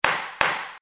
拍卖敲锤子.mp3